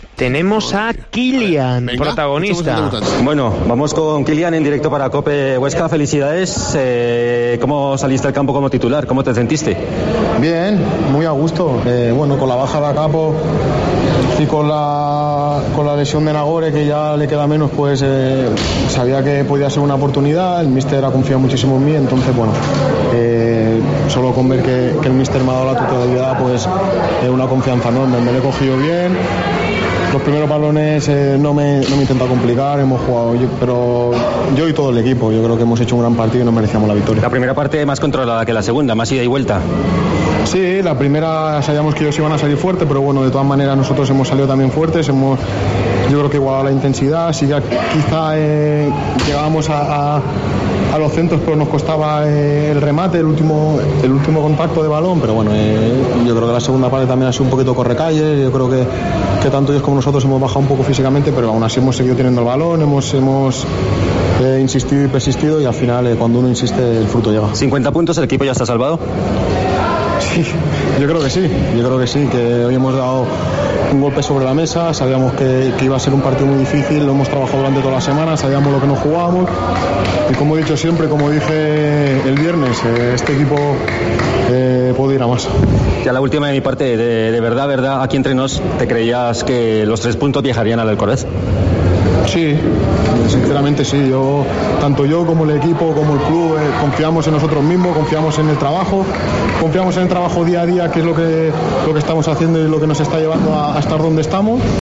en zona mixta